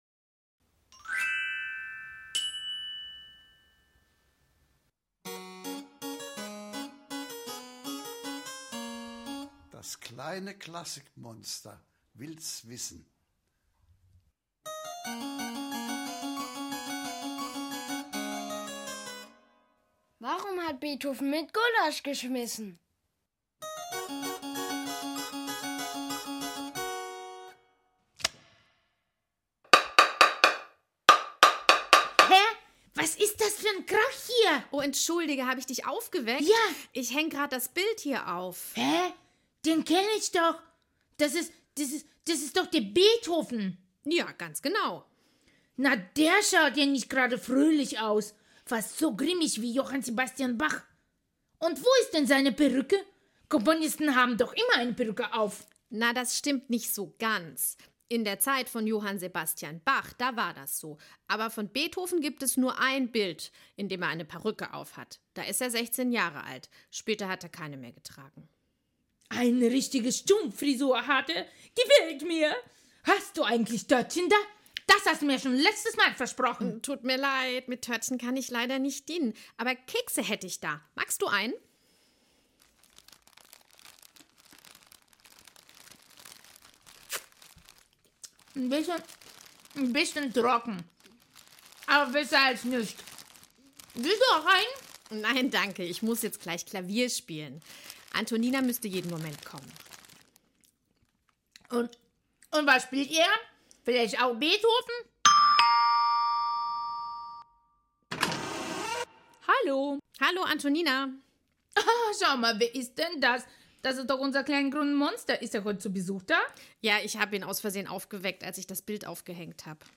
März 2021 Nächste Episode download Beschreibung Teilen Abonnieren Das kleine Monster wird unsanft geweckt und hört Musik aus Beethovens 5. Violinsonate, der berühmten Frühlingssonate. Neugierig und begeistert wie immer, lässt es sich ganz genau erklären, was eine Sonate ist, wieviel Sätze sie hat und wieso Beethoven eigentlich keine Perücke aufhatte.